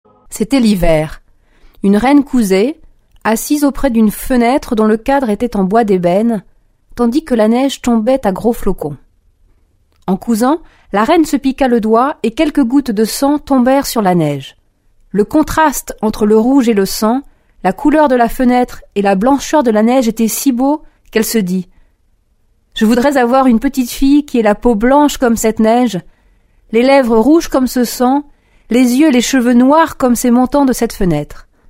Conte des frères Grimm Musique : Les 4 saisons de Vivaldi Comédienne